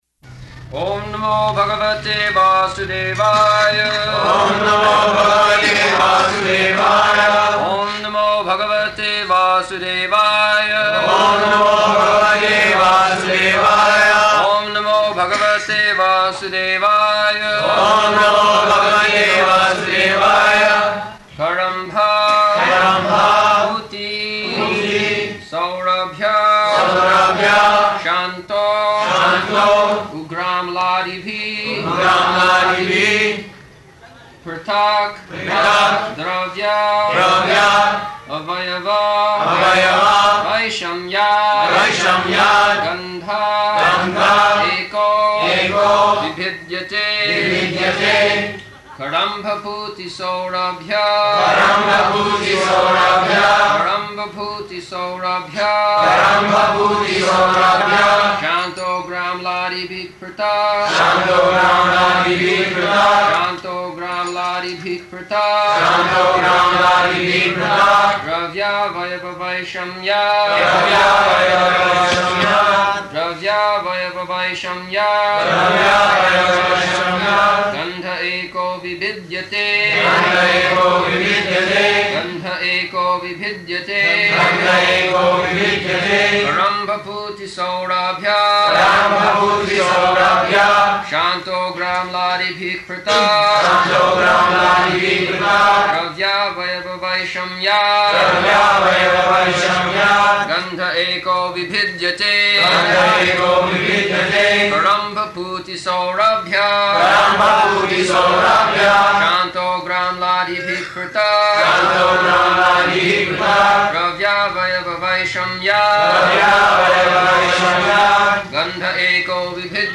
January 20th 1975 Location: Bombay Audio file
[devotees repeat] [leads chanting of verse, etc.]